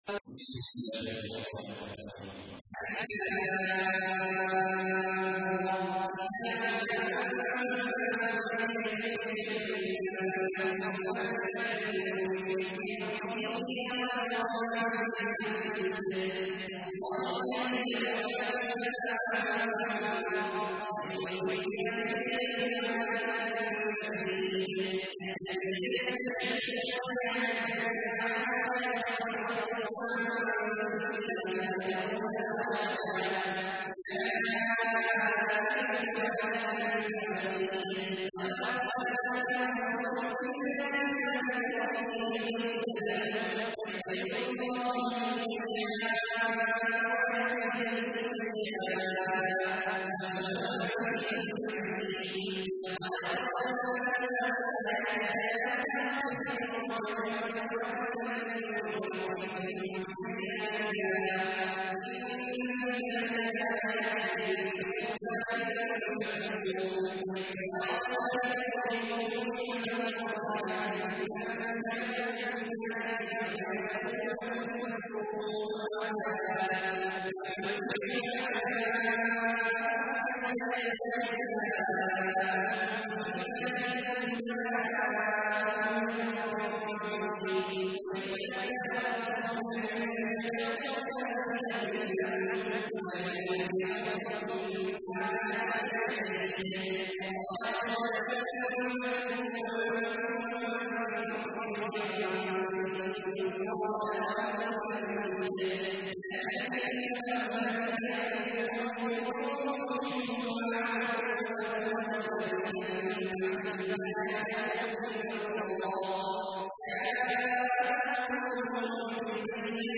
تحميل : 14. سورة إبراهيم / القارئ عبد الله عواد الجهني / القرآن الكريم / موقع يا حسين